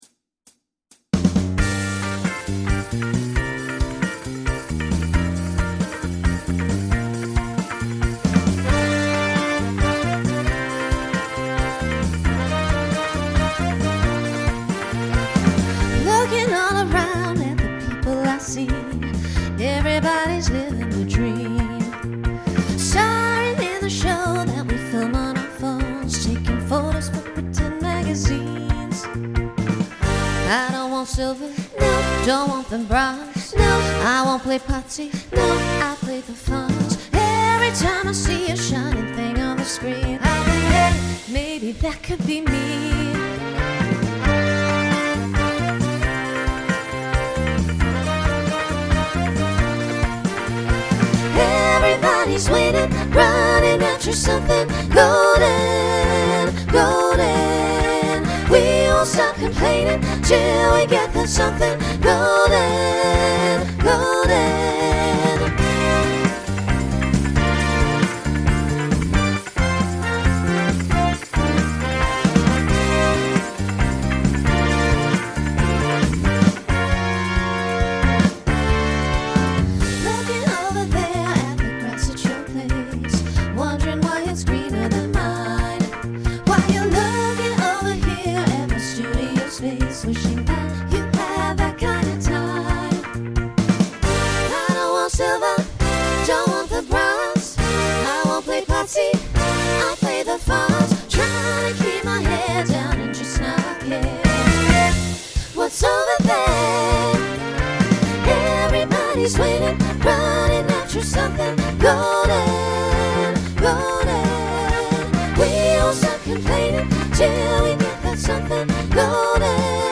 Starts with a treble trio, then SSA, then TTB, then SATB.